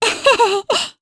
Mediana-Vox_Happy1_jp.wav